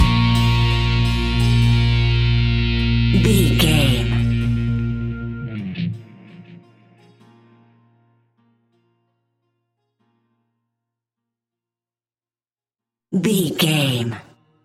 In-crescendo
Thriller
Aeolian/Minor
scary
tension
ominous
dark
suspense
eerie
Horror Pads
Horror Synths
Horror Ambience